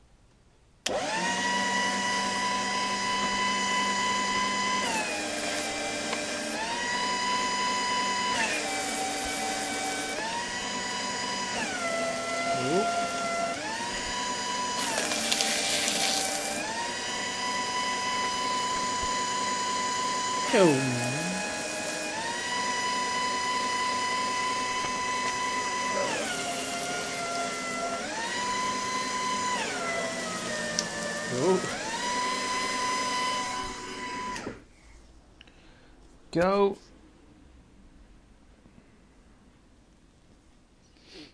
Shredding Paper